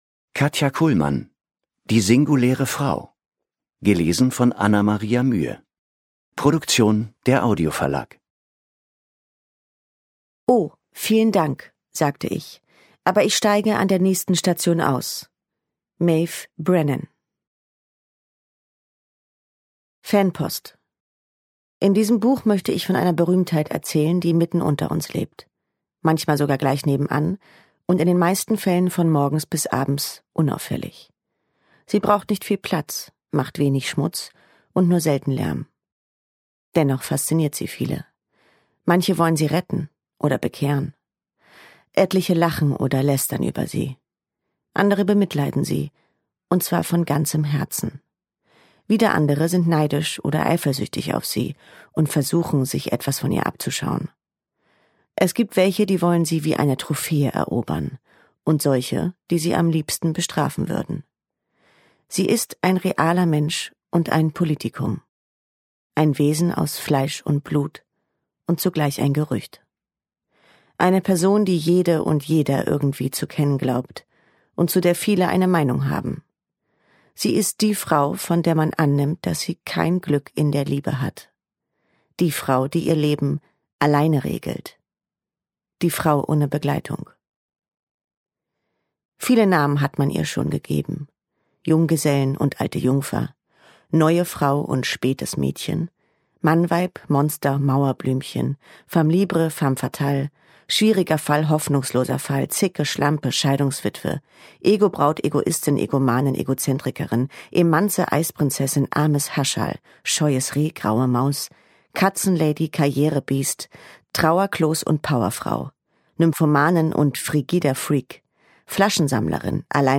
Die Singuläre Frau Ungekürzte Lesung mit Anna Maria Mühe
Anna Maria Mühe (Sprecher)